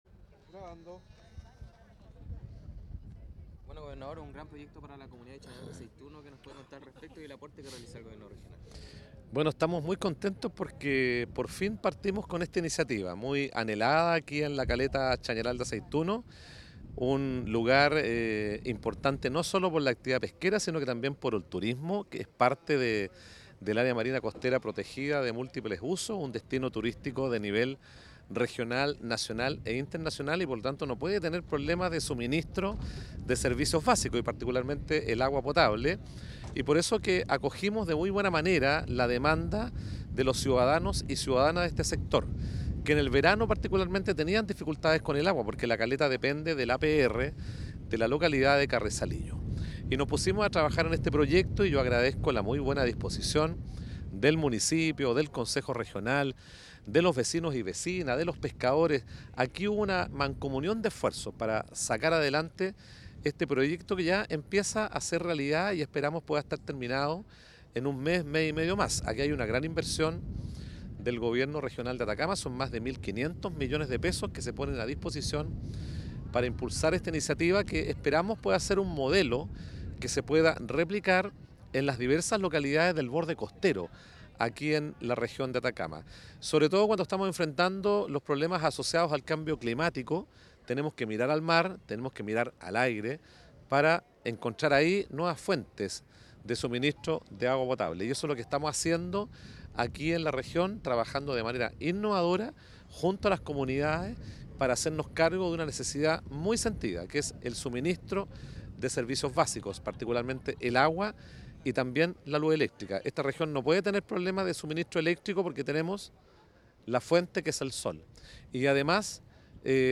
En una ceremonia encabezada por el Gobernador de Atacama, Miguel Vargas Correa, y el alcalde de Freirina, César Orellana, se firmó el contrato para la instalación de una planta desalinizadora en Caleta Chañaral de Aceituno.
Gobernador-Miguel-Vargas-Correa-1.mp3